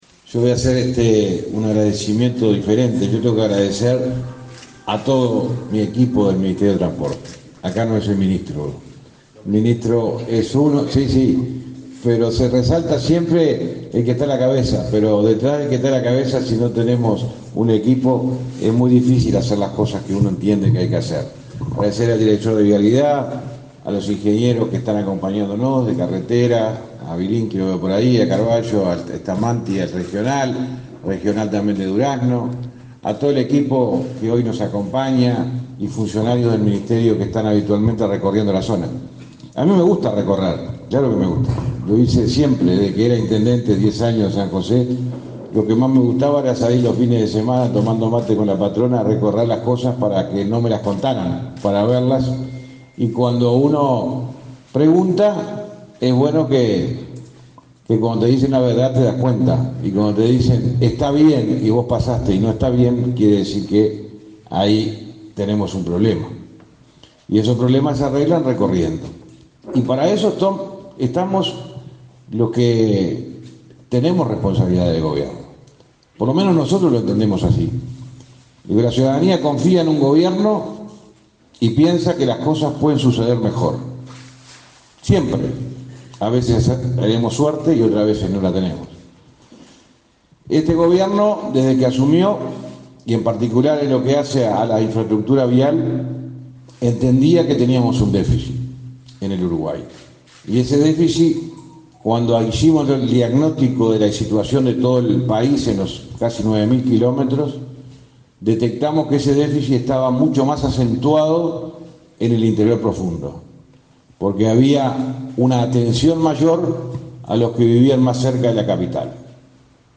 Palabras del ministro de Transporte y Obras Públicas, José Luis Falero, en inauguración de obras viales
Palabras del ministro de Transporte y Obras Públicas, José Luis Falero, en inauguración de obras viales 27/06/2023 Compartir Facebook X Copiar enlace WhatsApp LinkedIn El ministro de Transporte y Obras Públicas, José Luis Falero, participó, este 27 de junio, en la inauguración de obras viales en la ruta n.° 7, en la localidad de Cerro Chato.